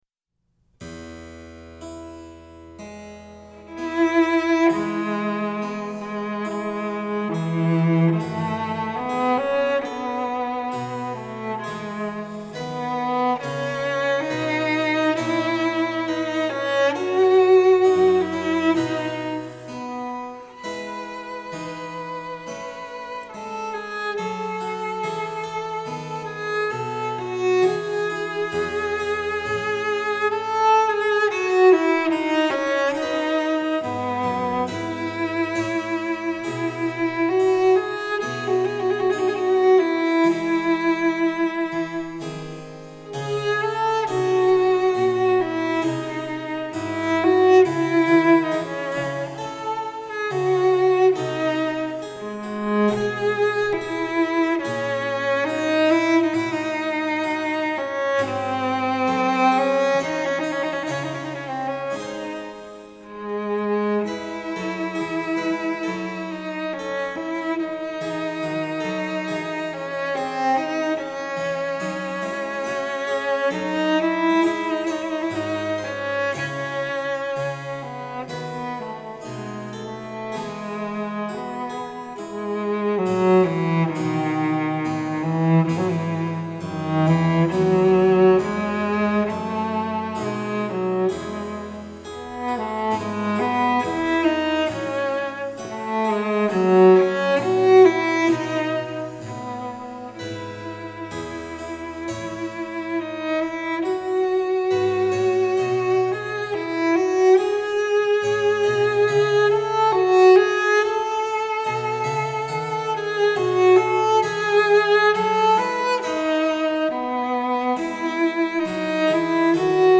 Composer: Performed by Cellist